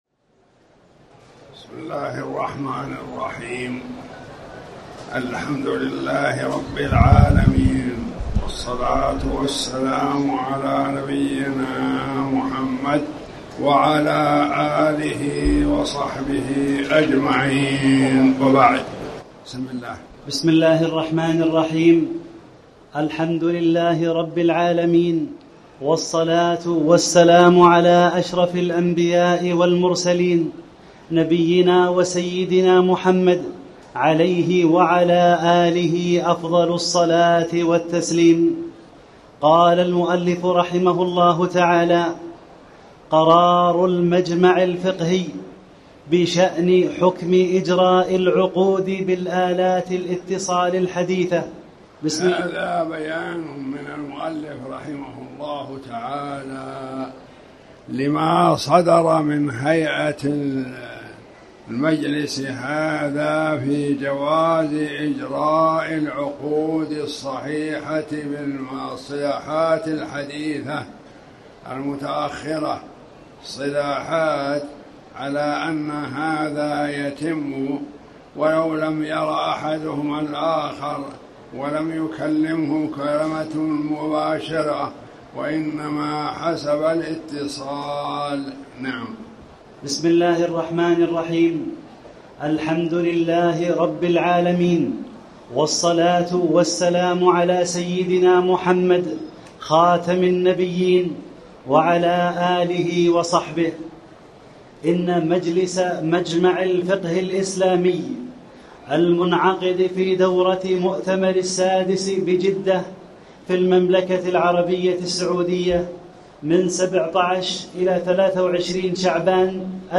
تاريخ النشر ٦ ربيع الثاني ١٤٣٩ هـ المكان: المسجد الحرام الشيخ